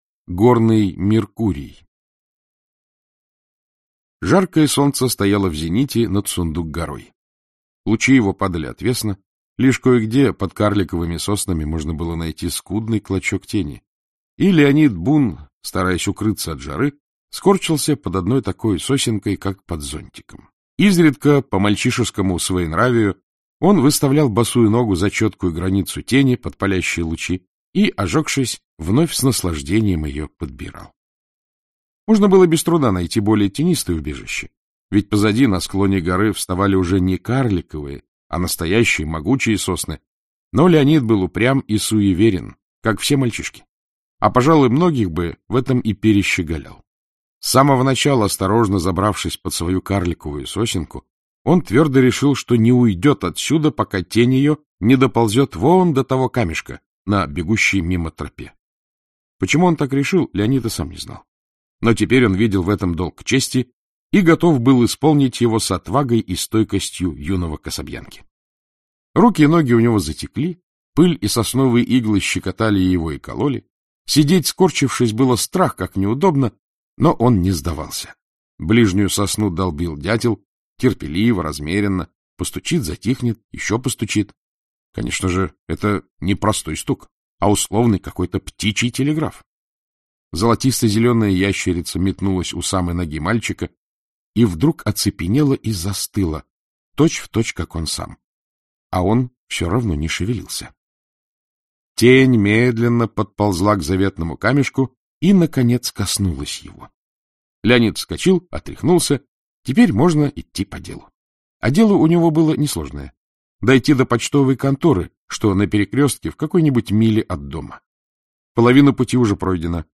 Аудиокнига Маленький старатель и другие рассказы | Библиотека аудиокниг